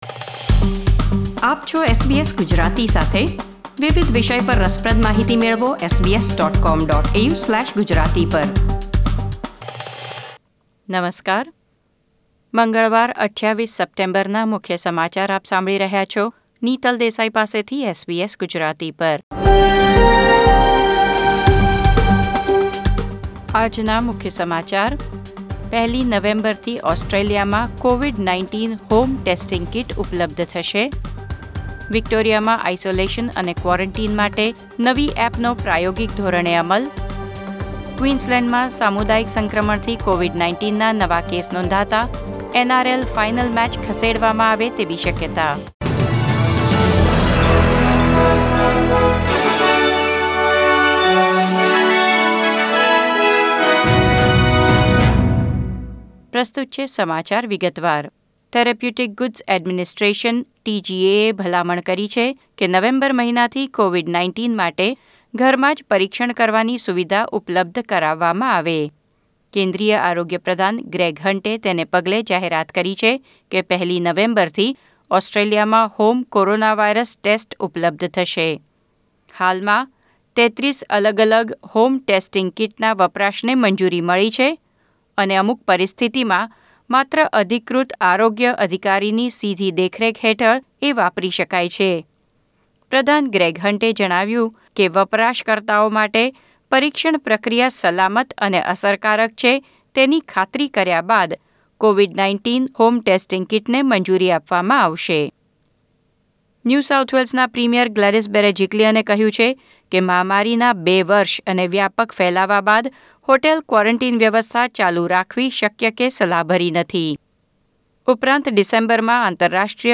SBS Gujarati News Bulletin 28 September 2021